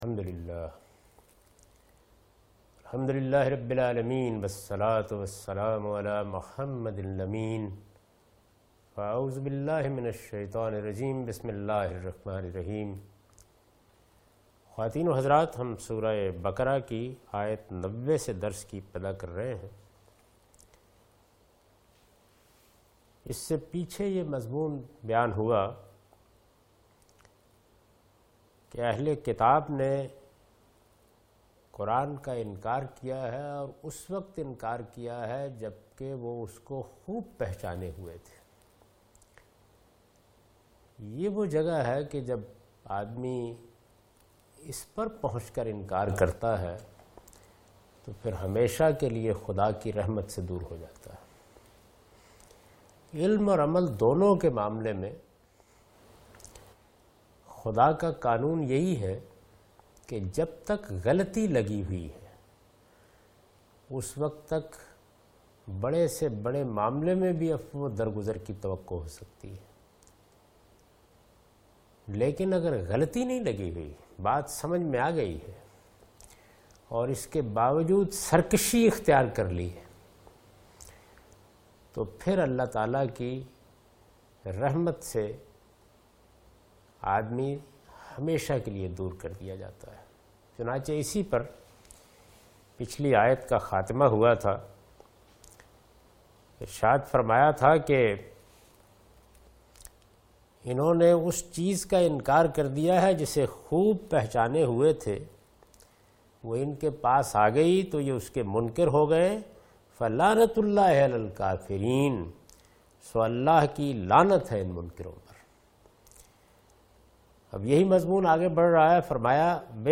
Surah Al-Baqarah - A lecture of Tafseer-ul-Quran – Al-Bayan by Javed Ahmad Ghamidi. Commentary and explanation of verse 90,91,92,93,94 and 95 (Lecture recorded on 17th Oct 2013).